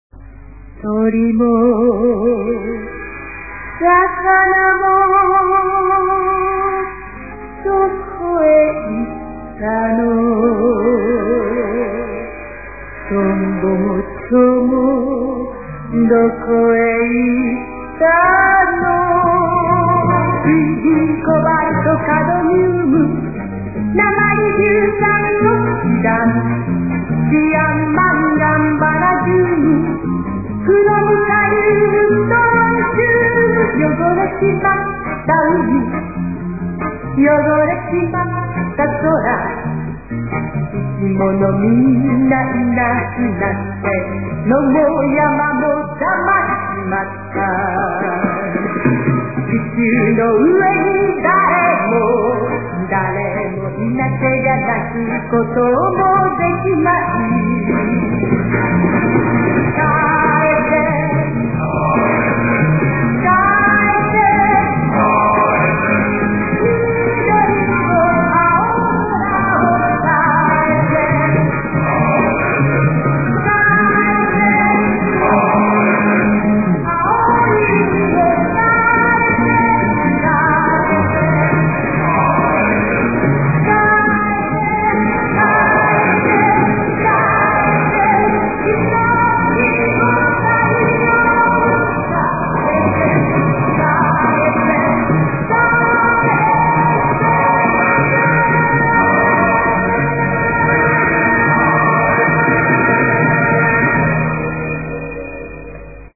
Japanese hippies sing to save Earth